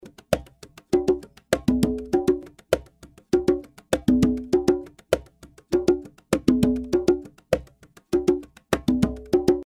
100 BPM conga loops part B (9 variations)
🪘 Real Conga Loops – Salsa Groove at 100 BPM 🪘
Add Latin flavor to your track with authentic conga loops, performed by a professional percussionist and recorded at 100 BPM.
🎙 Studio-Quality Recording Captured with high-end gear for a rich, natural sound:
🎵 Perfect for: Latin Salsa Afro-Cuban grooves Fusion, world music, and cinematic rhythms 📦 Includes 9 unique loop variations 💡 Preview demo includes added room reverb for illustration only 🎯 Just drag, drop, and groove!